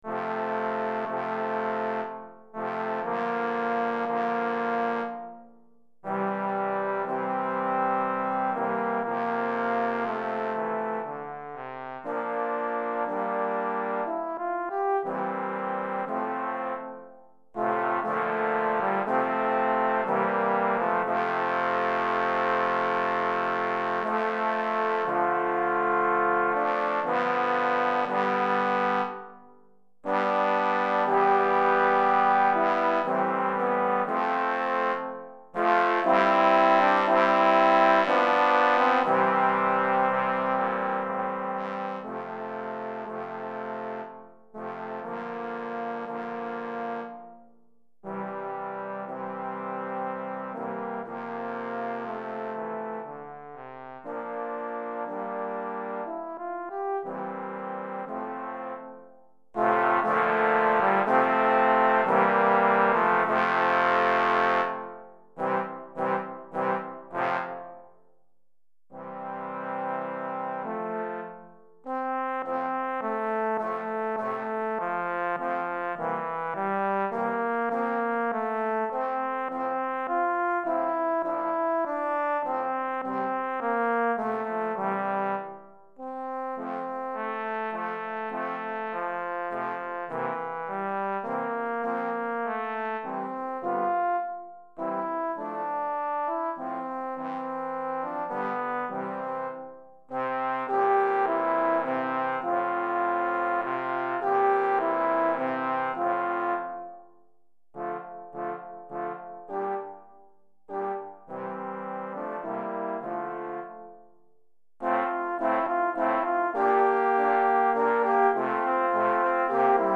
3 Trombones et Trombone Basse